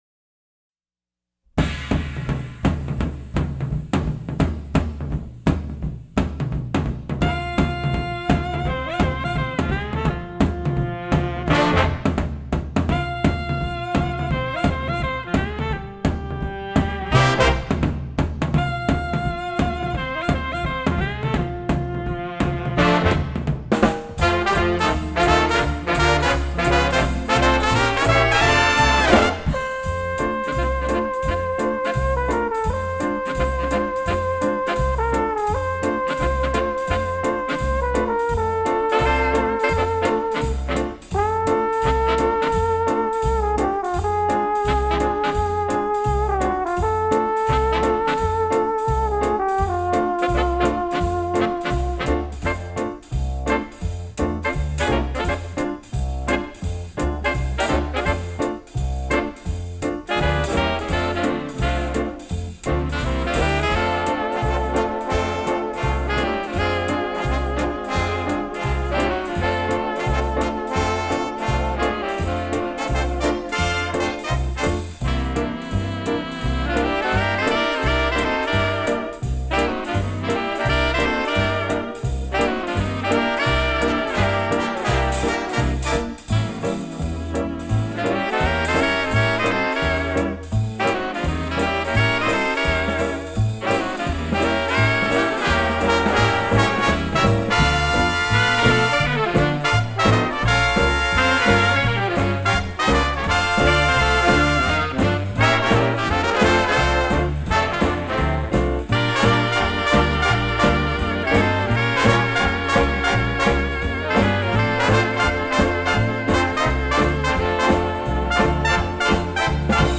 Instrumentation: jazz band
jazz, standards, swing